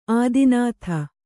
♪ ādinātha